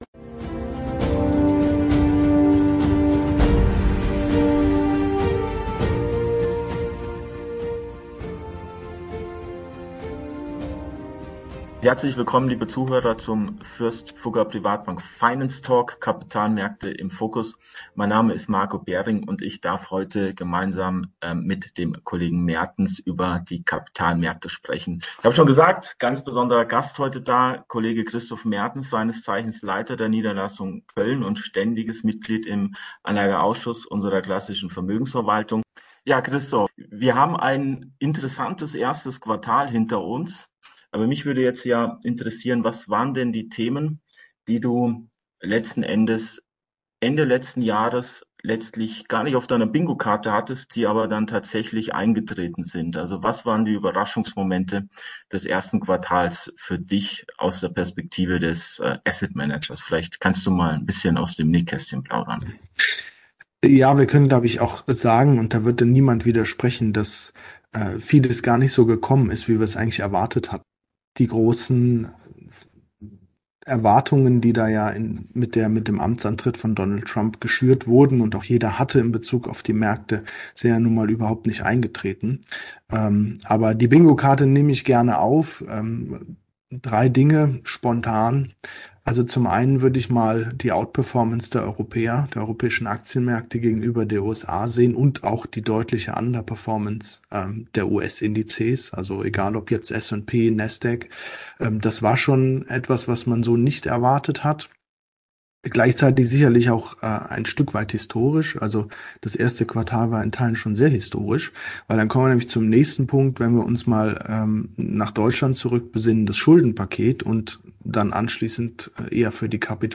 Abwechselnd laden sie Gäste zum Interview ein.